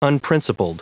Prononciation du mot unprincipled en anglais (fichier audio)
Prononciation du mot : unprincipled